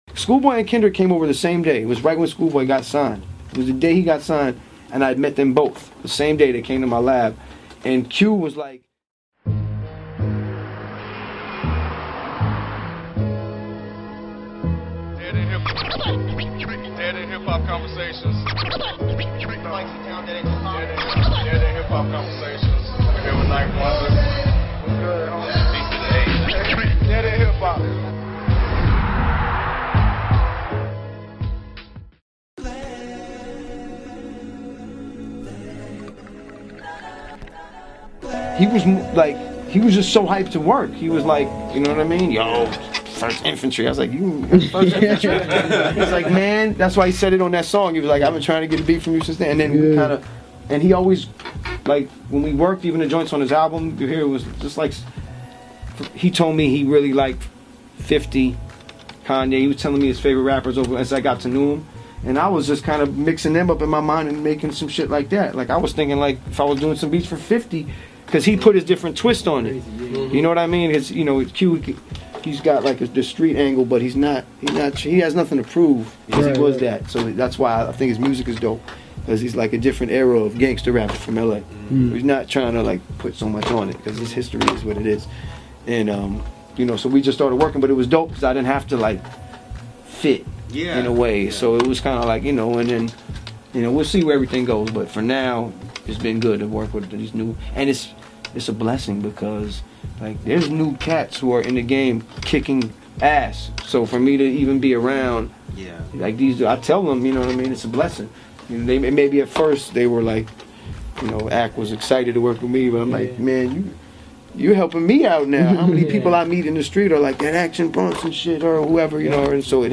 DeadEndHipHop x The Alchemist Interview Pt.2